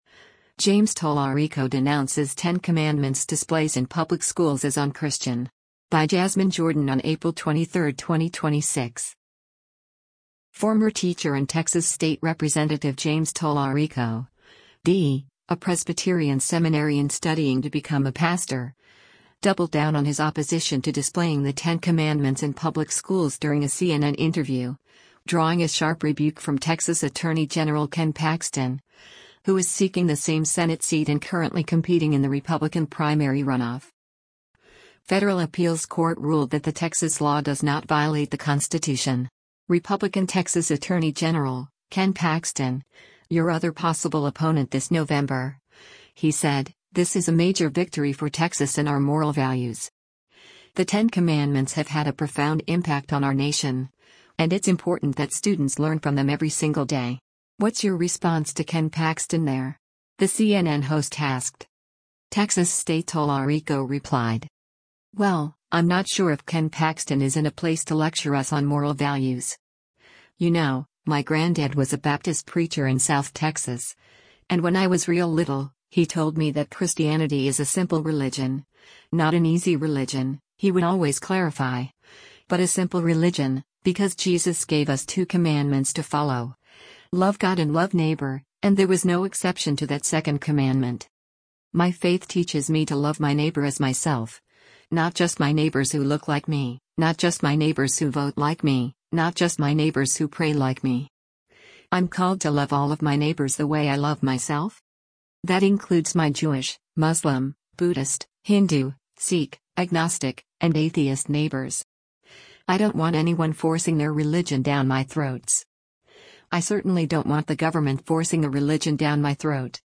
Former teacher and Texas state Rep. James Talarico (D), a “Presbyterian seminarian studying to become a pastor,” doubled down on his opposition to displaying the Ten Commandments in public schools during a CNN interview, drawing a sharp rebuke from Texas Attorney General Ken Paxton, who is seeking the same Senate seat and currently competing in the Republican primary runoff.